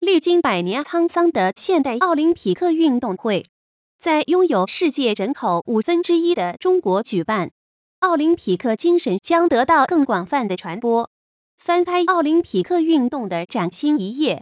無背景音樂樣例 (wav格式)